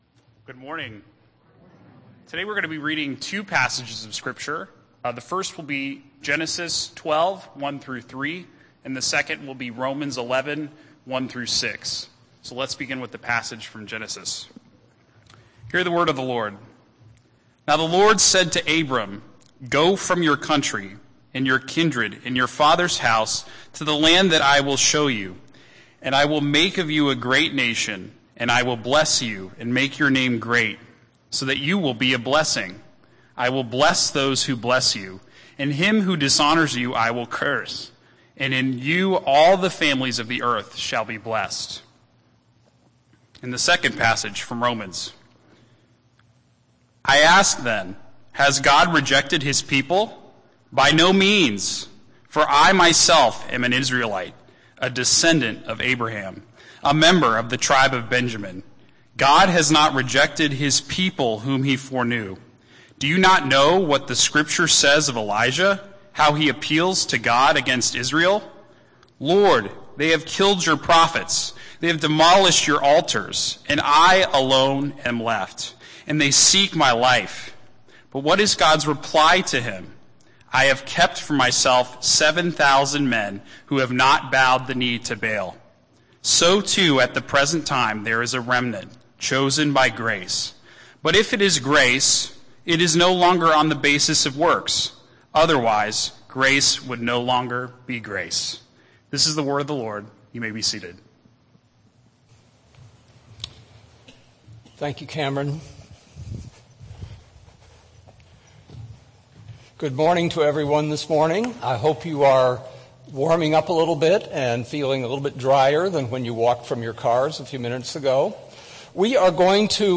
Morning Worship at Redeemer Bible Church